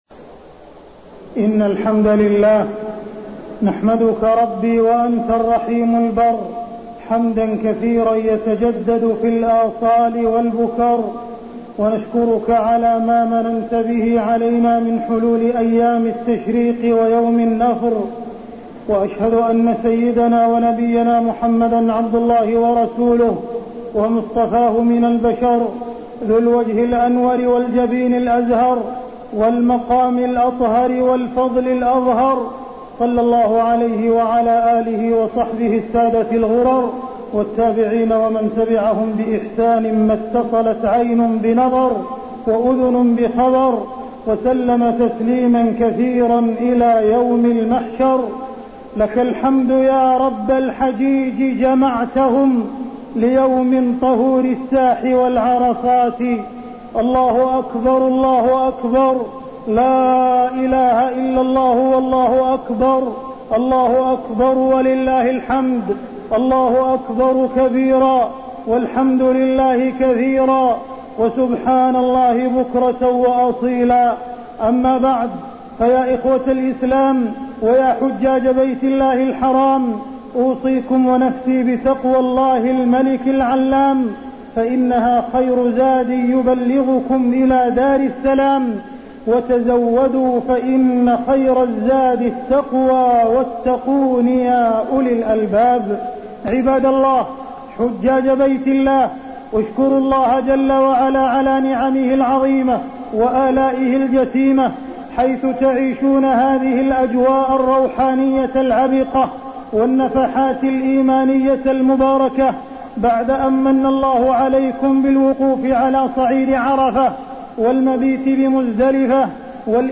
تاريخ النشر ١٢ ذو الحجة ١٤٢٨ هـ المكان: المسجد الحرام الشيخ: معالي الشيخ أ.د. عبدالرحمن بن عبدالعزيز السديس معالي الشيخ أ.د. عبدالرحمن بن عبدالعزيز السديس ألا في الفتنة سقطوا